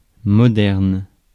Prononciation
Synonymes actuel avancé perfectionné nouveau Prononciation France: IPA: /mɔ.dɛʁn/ Le mot recherché trouvé avec ces langues de source: français Traduction 1.